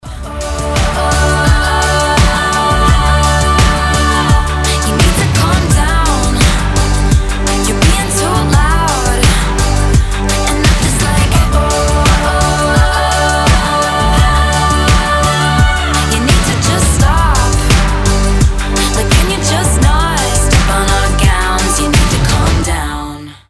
• Качество: 320, Stereo
поп
громкие
женский вокал
заводные
Electropop